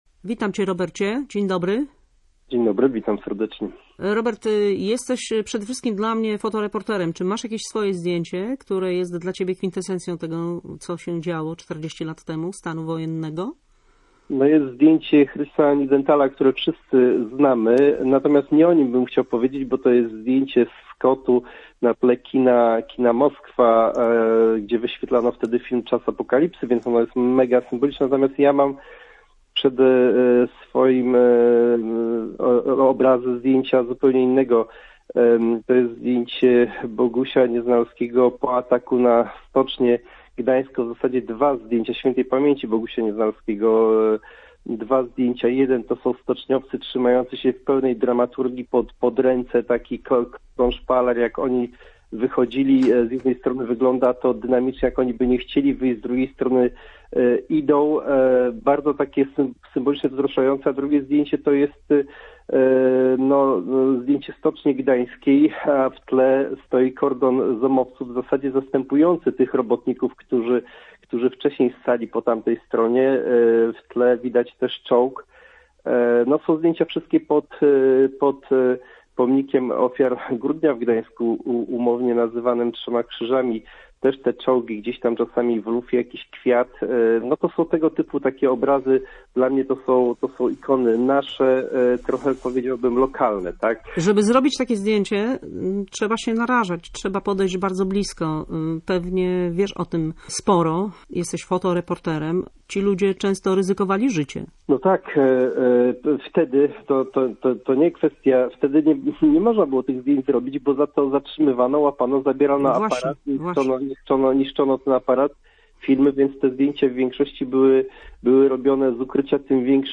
Popołudniowy „Gość Radia Gdańsk” z wielkim podziwem wspominał dawnych fotoreporterów.